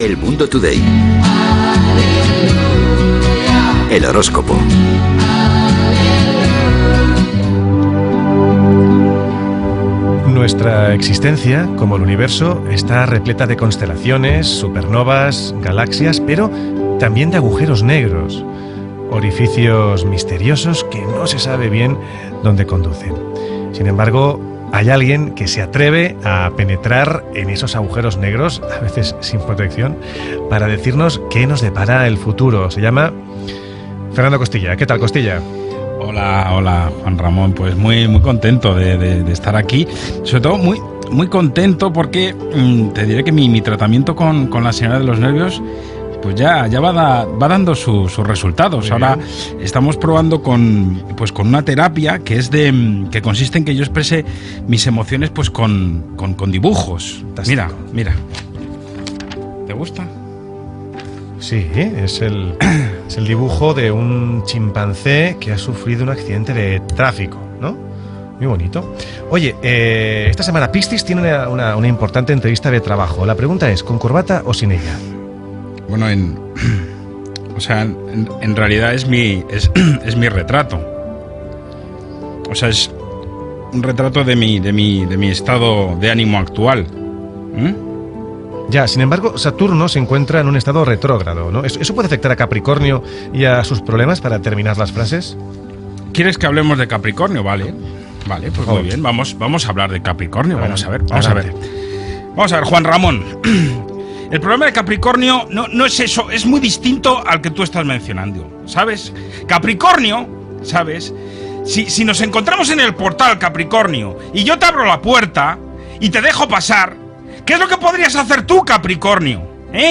Indicatiu de l'espai i l'horòscop
Entreteniment